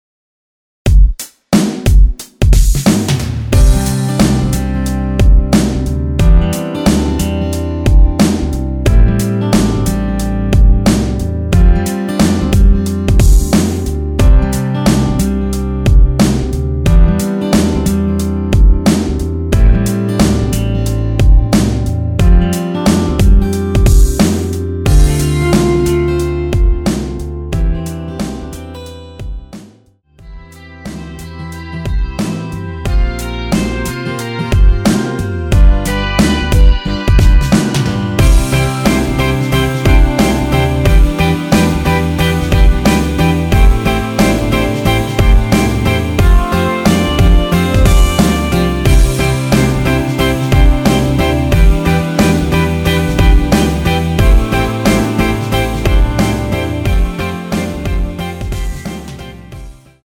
MR 입니다.
원곡의 보컬 목소리를 MR에 약하게 넣어서 제작한 MR이며